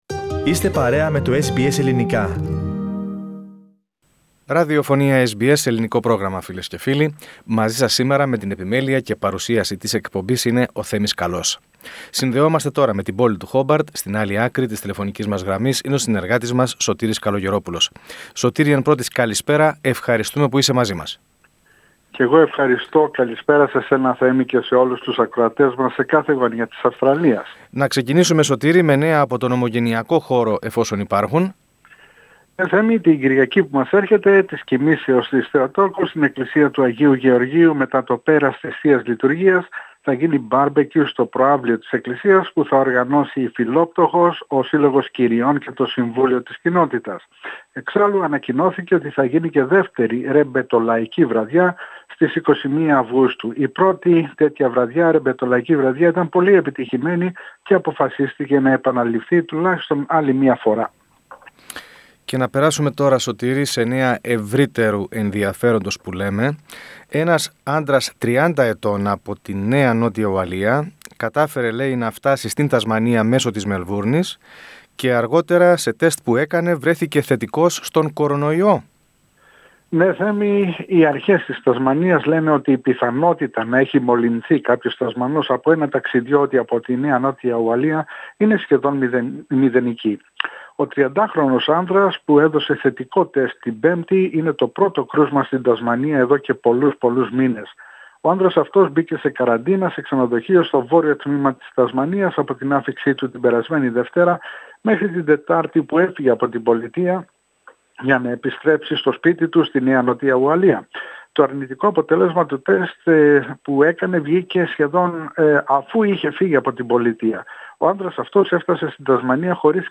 Πατήστε PLAY πάνω στην εικόνα για να ακούσετε την ανταπόκριση του SBS Greek/SBS Ελληνικά.